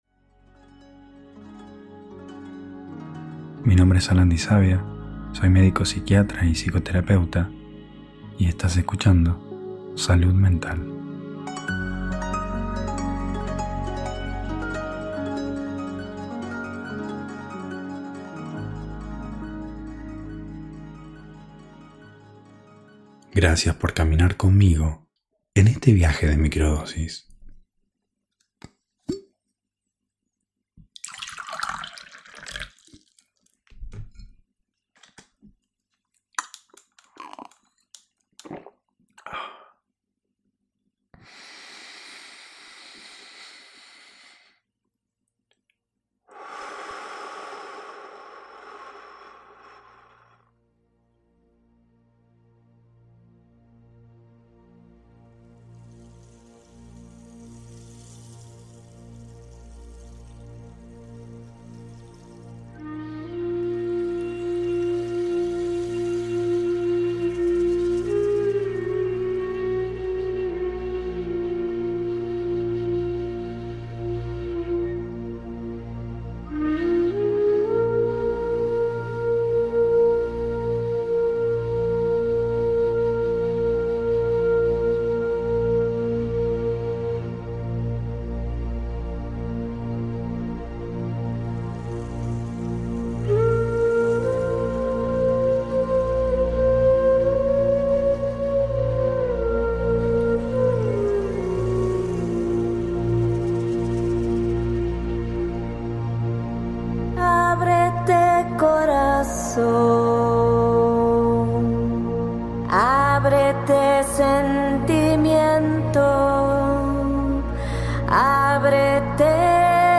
Ritual de Microdosis guiado - Máscaras